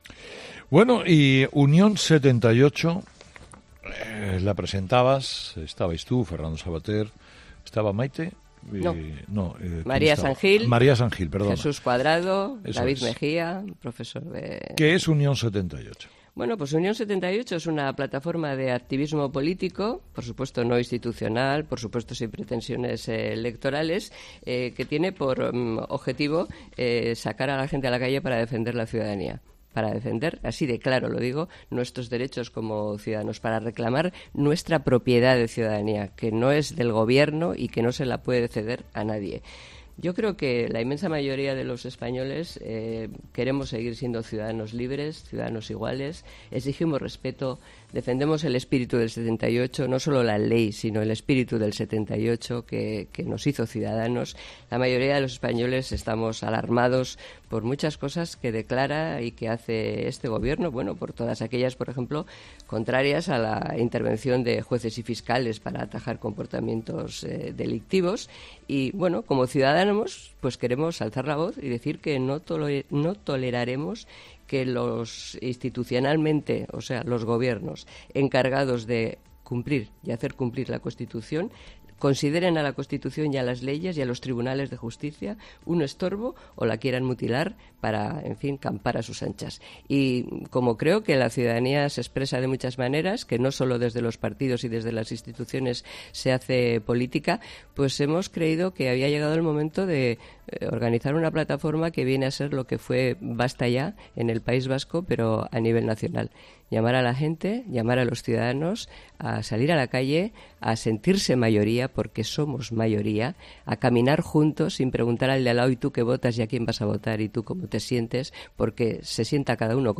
Díez ha explicado en el programa "Herrera en COPE" que la plataforma "tiene como objetivo sacar a la gente a la calle para defender nuestros derechos como ciudadanos.